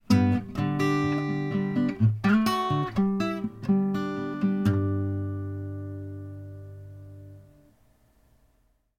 The mic sounds great for a $4 mic, with no 60 Hz hum, but there is still radio noise.
There is no 60 Hz hum. The mic picks up the sound of the computer but I don't think it is picking up radio noise. The sensitive mic is picking up the sound of the power supply fan.